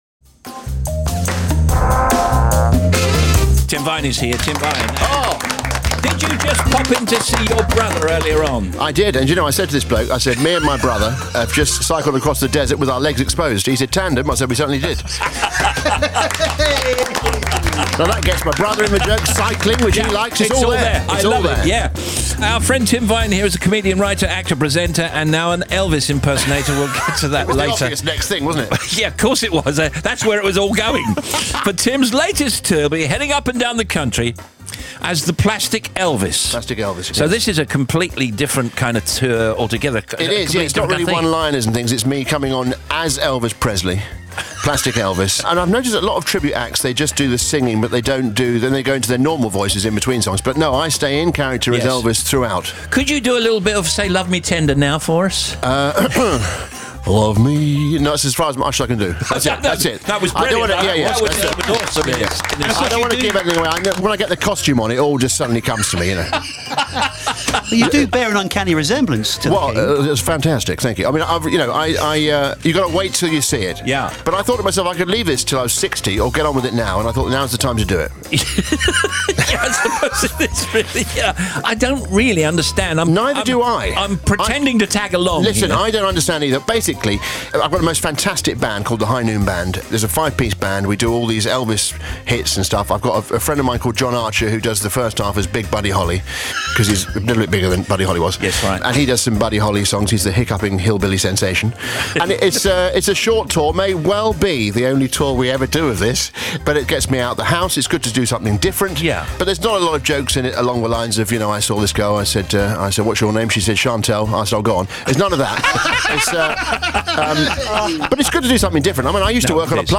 Here is a soundbite taken from Steve Wright’s Radio 2 show earlier this week, with Tim Vine talking about his Plastic Elvis tour.
Steve-Wright-show-Tim-Vine-interview.mp3